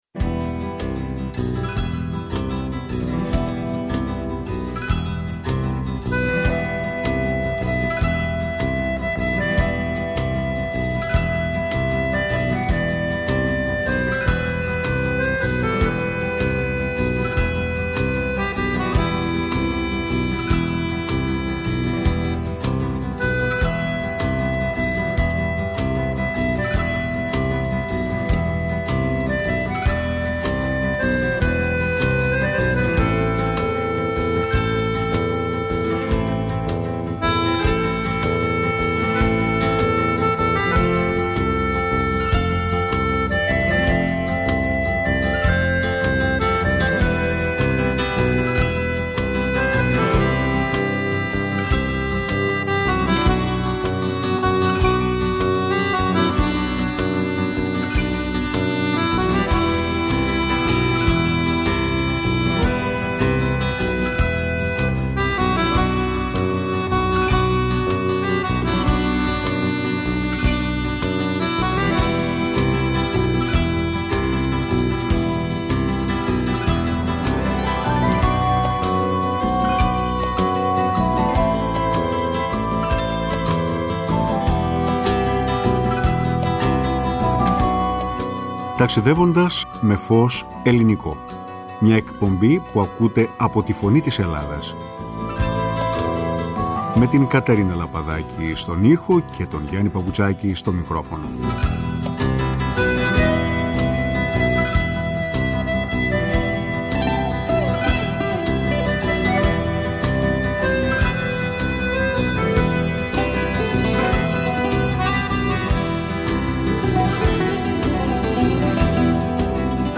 Μουσική